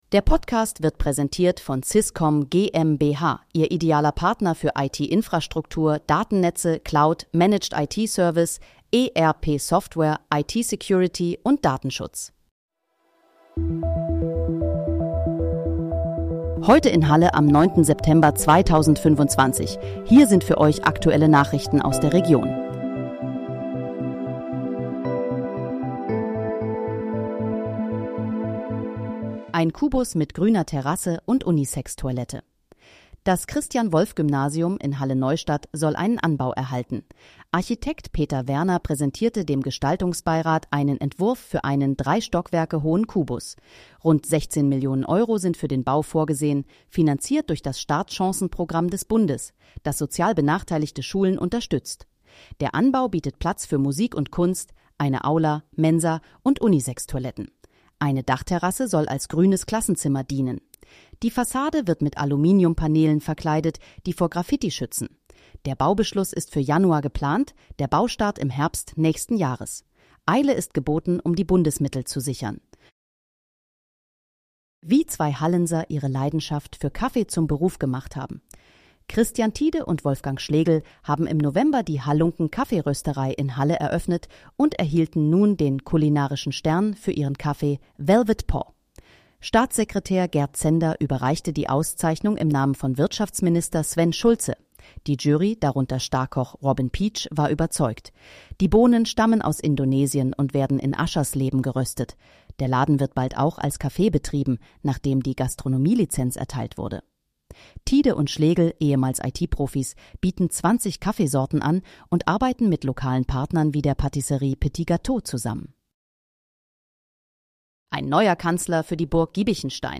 Heute in, Halle: Aktuelle Nachrichten vom 09.09.2025, erstellt mit KI-Unterstützung
Nachrichten